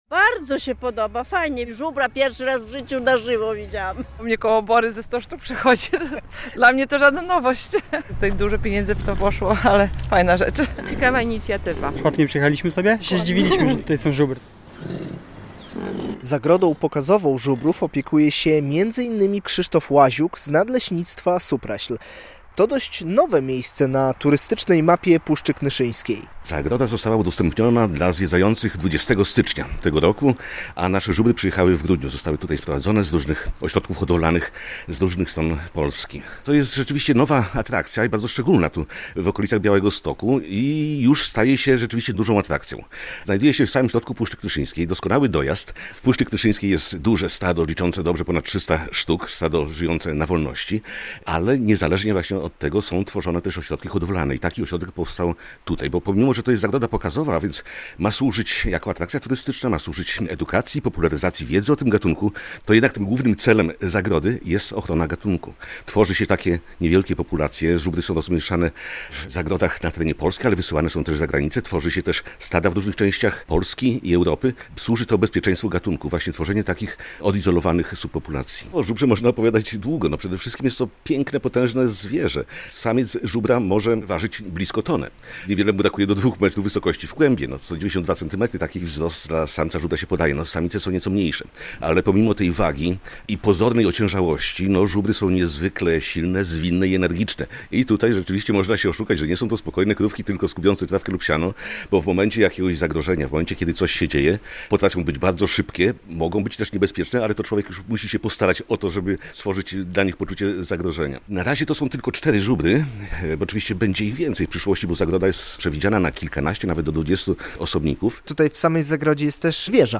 Odwiedzamy zagrodę pokazową żubrów w Kopnej Górze koło Supraśla - relacja